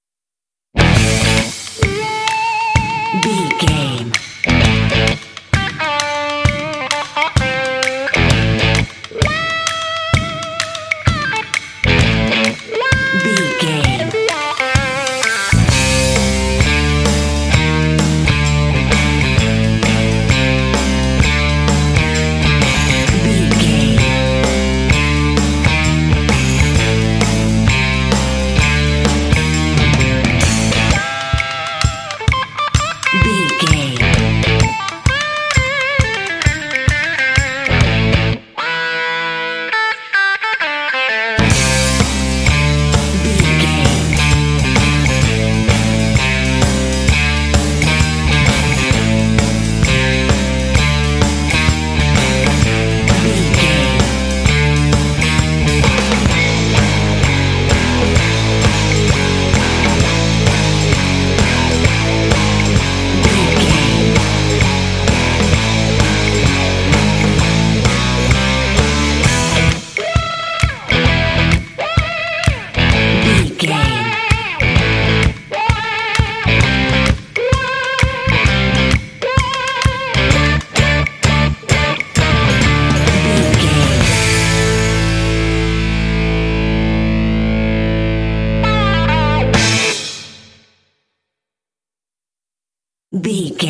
This groovy track is ideal for action and sports games.
Aeolian/Minor
groovy
electric guitar
percussion
drums
bass guitar
rock
classic rock